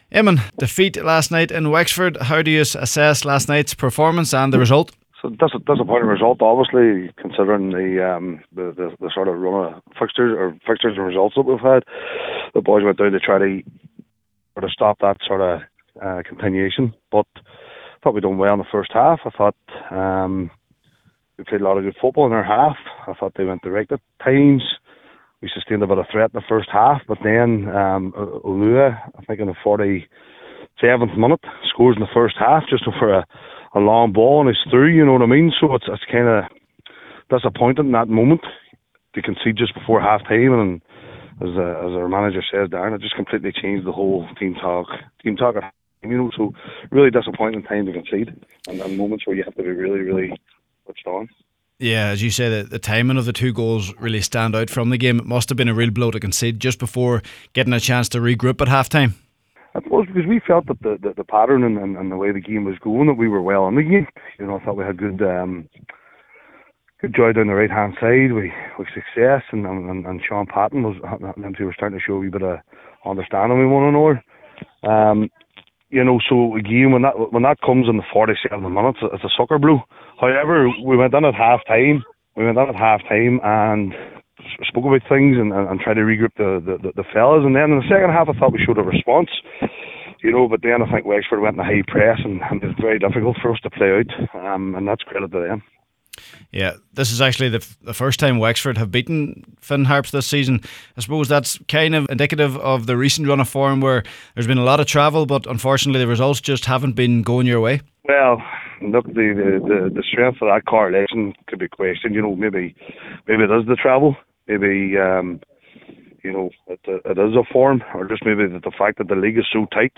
Here he is in conversation